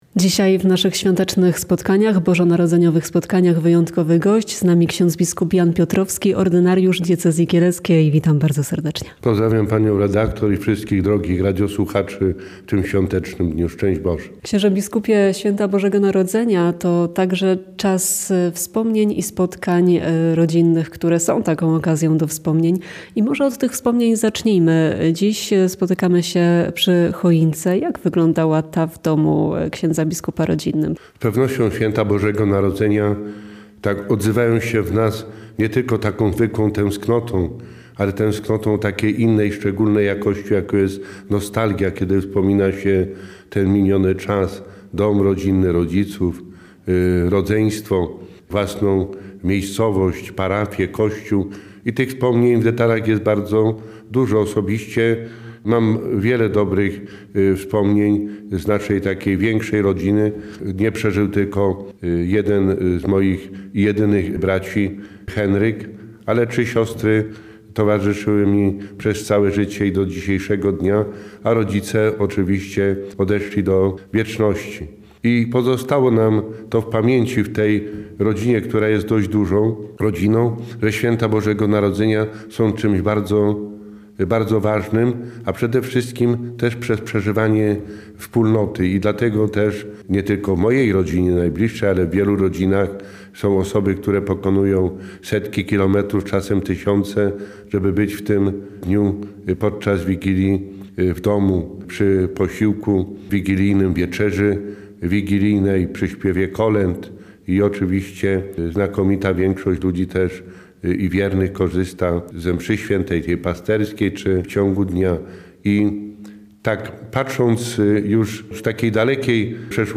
- Święta Bożego Narodzenia są bardzo ważne także przez przeżywanie wspólnoty. Dlatego, nie tylko w mojej, ale w wielu rodzinach, są osoby, które pokonują setki, czasem tysiące kilometrów, by być w tym dniu w domu, by być na wieczerzy wigilijnej, przy śpiewie kolęd - mówił w świątecznej rozmowie w Radiu Kielce biskup Jan Piotrowski.